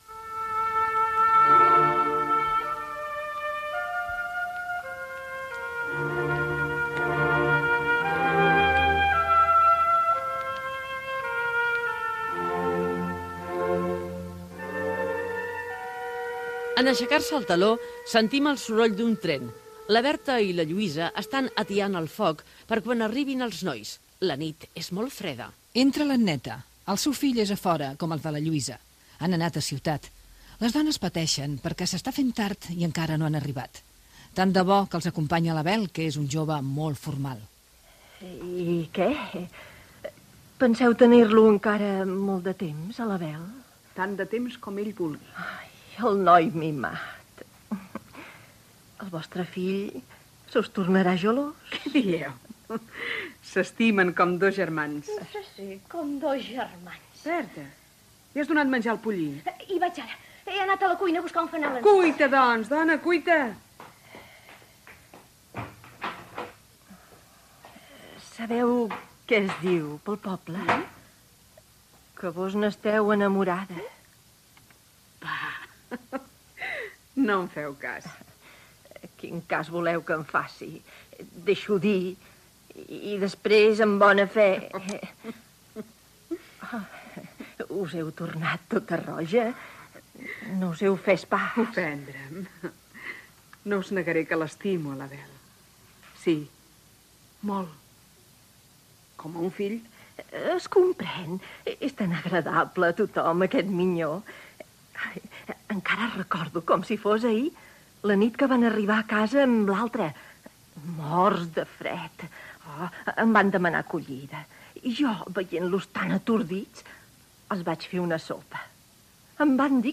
Fragment de la versió radiofònica de l'obra "La dama enamorada" de Joan Puig i Ferrater
Ficció